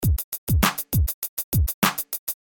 Качественно записать акустическую гитару